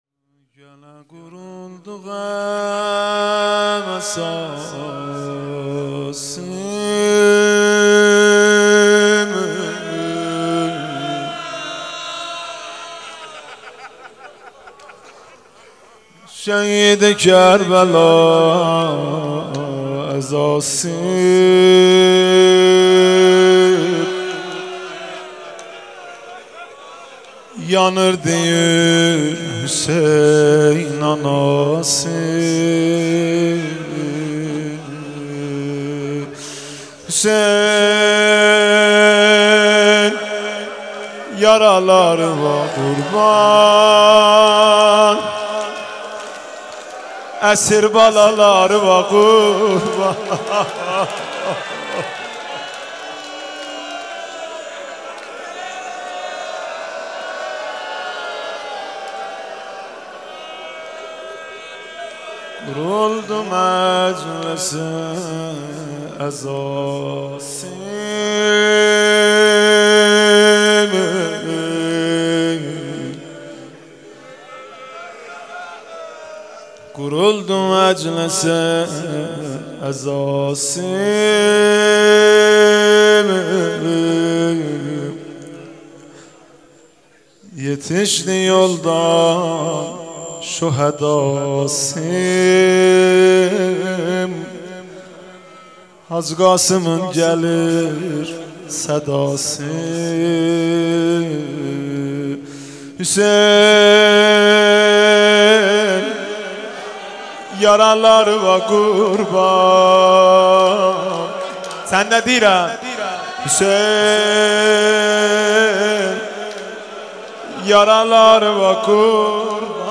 مداحی گینه قورولدی غم اساسی روضه ورود به محرم با صدای حاج مهدی رسولی در شب اول محرم الحرام ۱۴۴۲
مداحی گینه قورولدی غم اساسی روضه ورود به محرم با صدای حاج مهدی رسولی در شب اول محرم الحرام ۱۴۴۲ (مصادف با پنجشنبه سی ام مرداد ماه ۱۳۹۹ ش) در هیئت ثاراللّه (رهروان امام و شهدا)